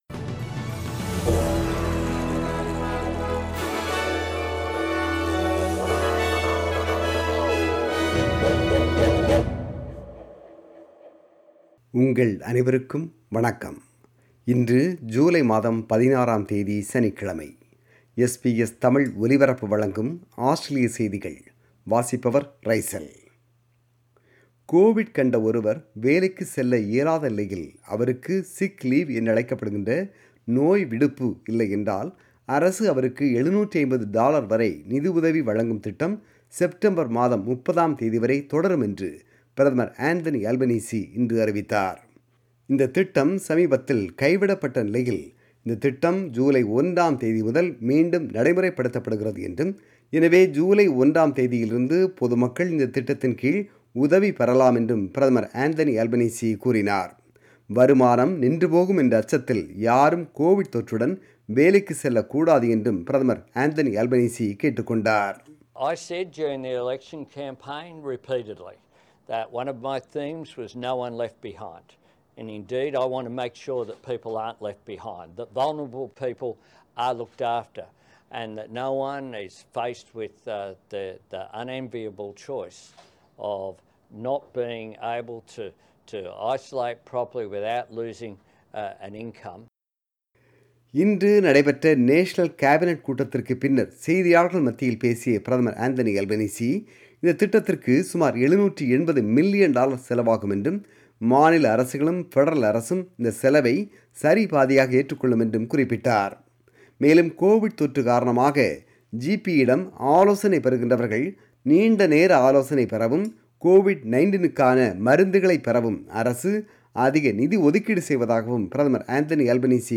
Australian News: 16 July 2022 – Saturday